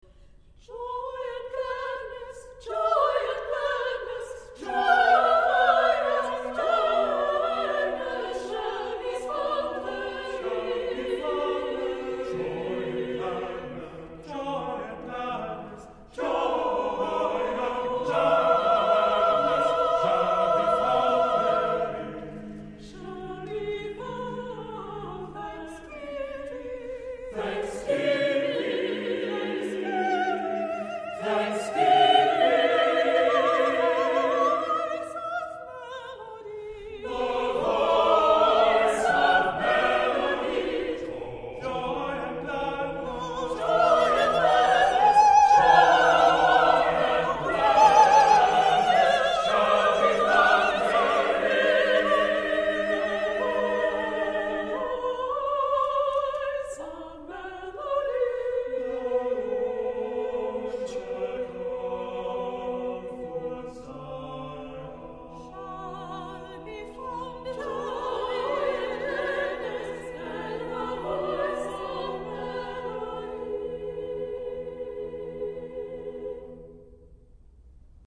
Accompaniment:      A Cappella, Soprano Solo
Music Category:      Choral